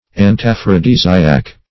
Antiaphrodisiac \An`ti*aph`ro*dis"i*ac\